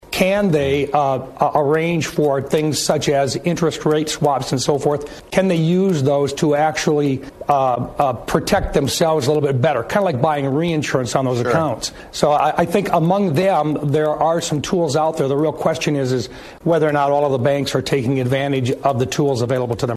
Appearing on NBC’s “Meet the Press” on Sunday, Rounds said banks should have the tools they need to restore stability.